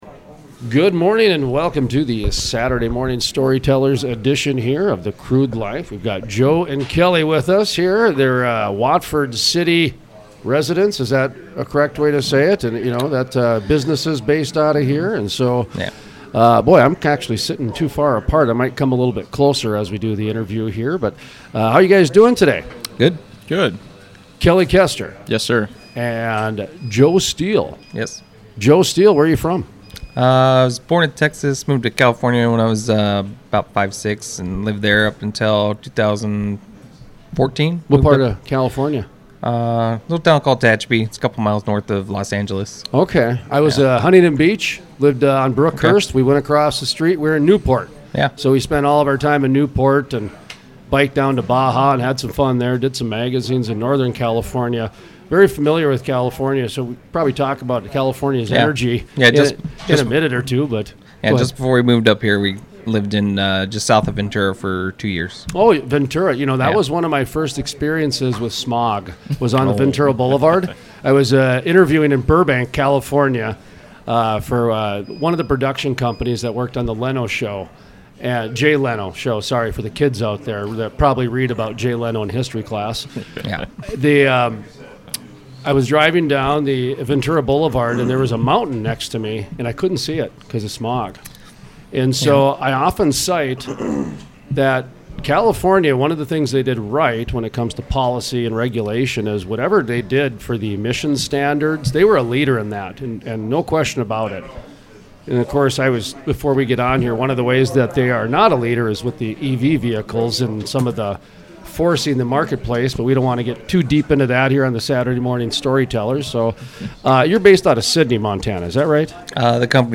Full Length Interviews Planting Roots In The Bakken Play Episode Pause Episode Mute/Unmute Episode Rewind 10 Seconds 1x Fast Forward 10 seconds 00:00 / 20 Minutes Subscribe Share RSS Feed Share Link Embed